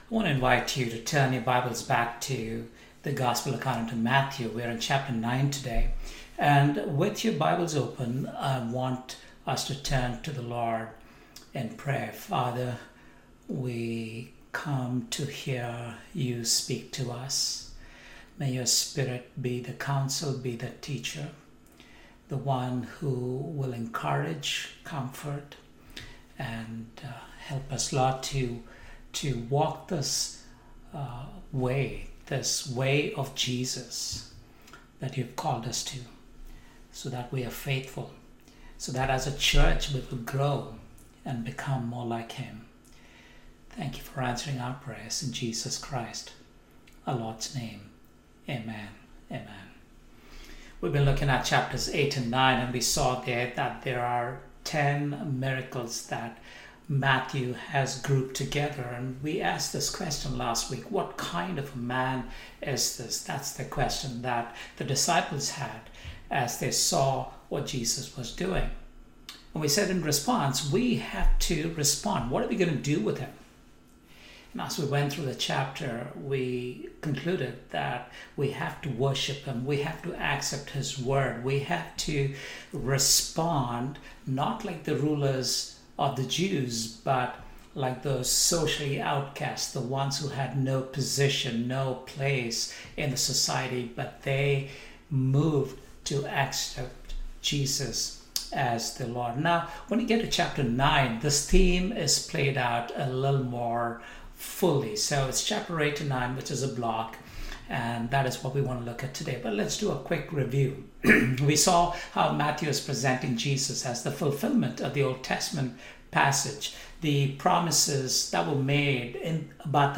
Matthew 2022 Passage: Matthew 9 Service Type: Sunday AM